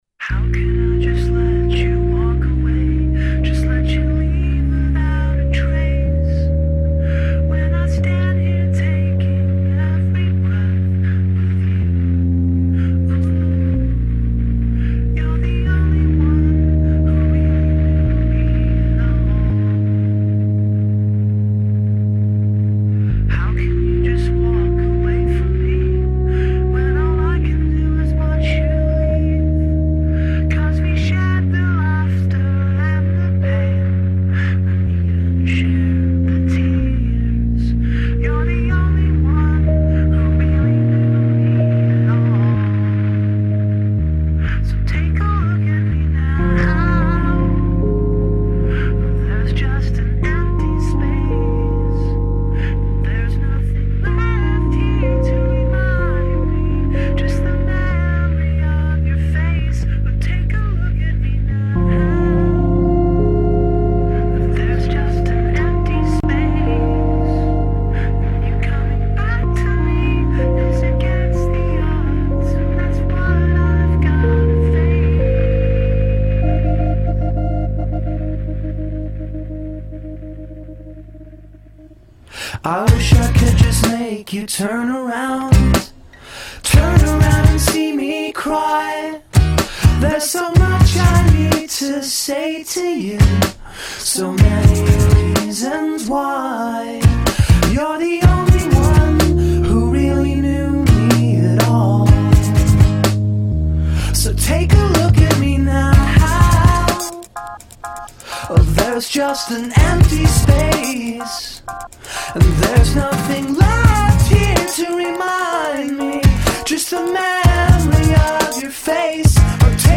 I discovered more than a few cover songs.